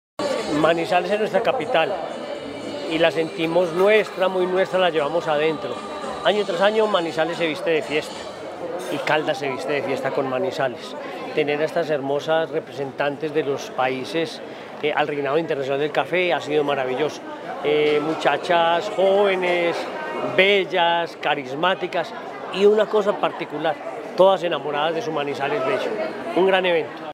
Gobernador de Caldas, Henry Gutiérrez.